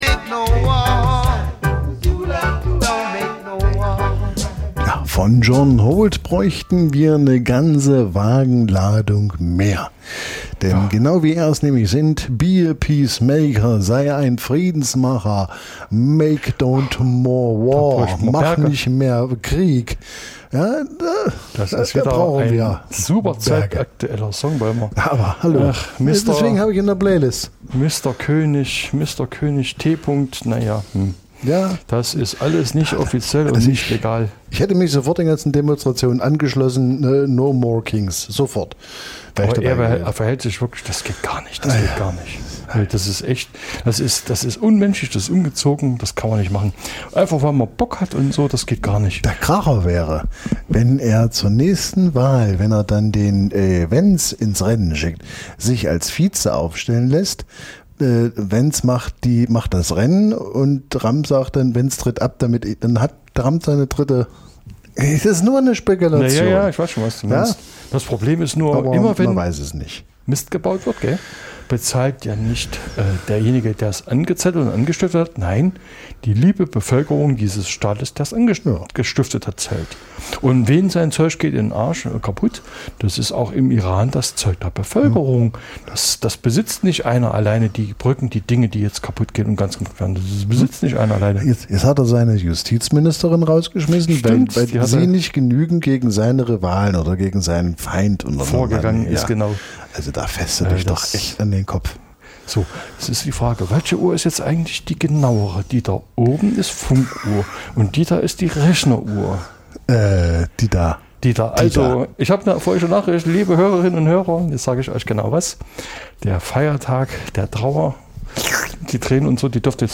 Reggae, Ska, Dub Dein Browser kann kein HTML5-Audio.
Reggae, Ska, Dub von alt bis neu.